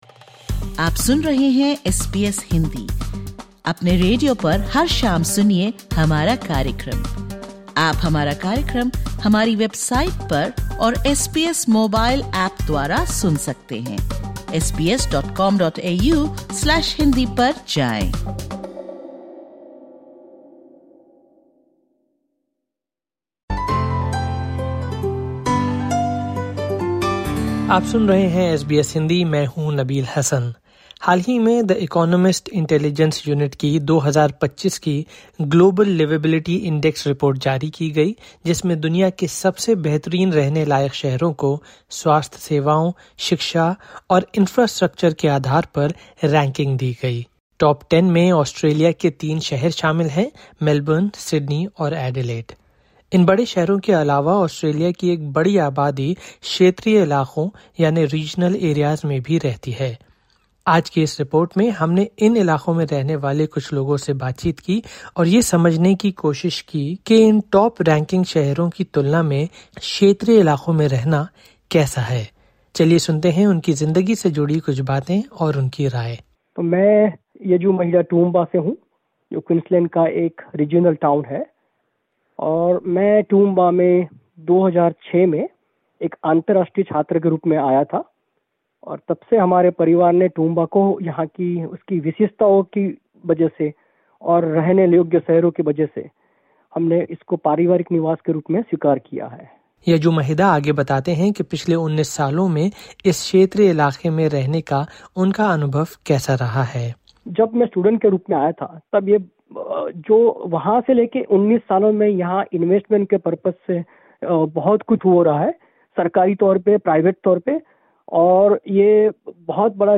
In this podcast, SBS Hindi explores life in regional Australia through voices from the Indian community. The discussion is based on the 2025 Global Liveability Index, which ranked Melbourne, Sydney, and Adelaide among the world’s top 10 most liveable cities, based on healthcare, education, and infrastructure.
We speak to residents in Townsville, Toowoomba, Taree, and Newcastle about their everyday lives and the challenges they face, from access to services to a sense of belonging.